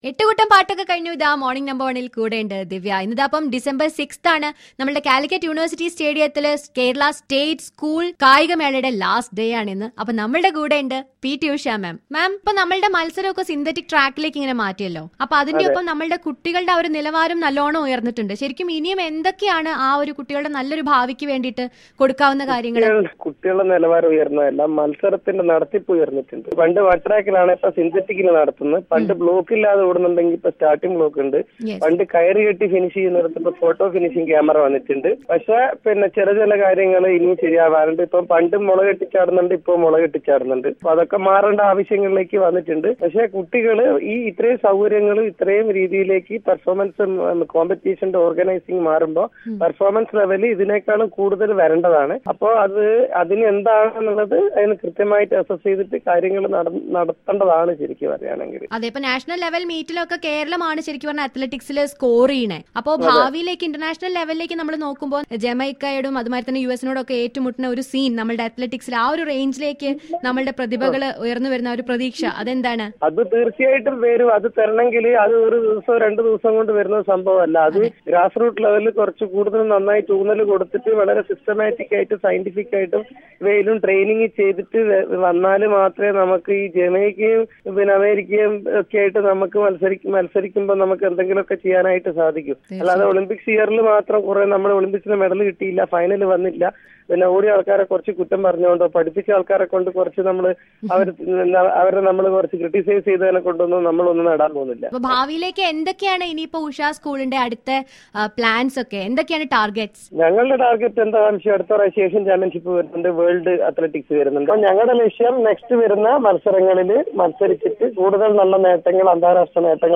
P.T. USHA CHAT REGARDING STATE SCHOOL SPORTS MEET.